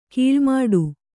♪ kīḷmāḍu